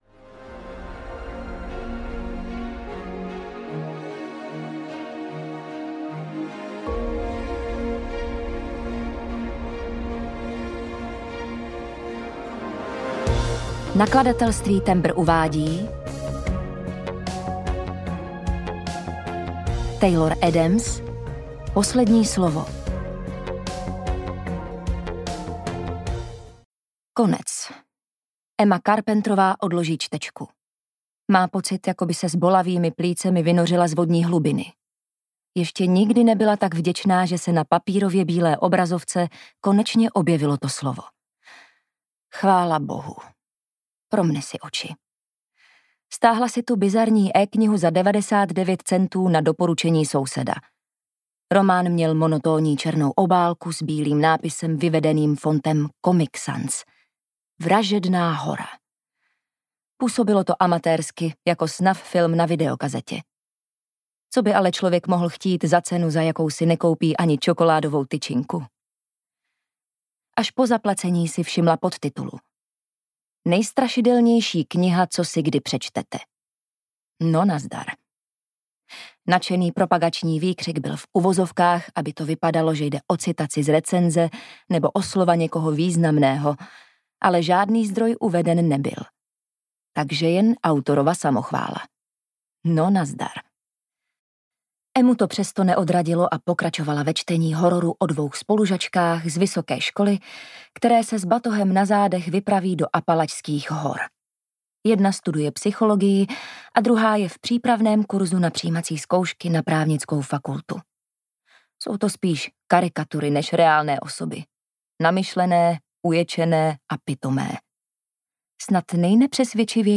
Poslední slovo audiokniha
Ukázka z knihy